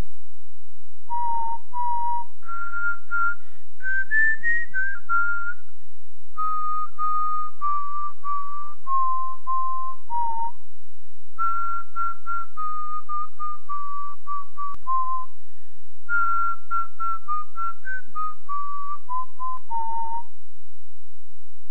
- Whistling a familiar tune (File size 1.8 MB)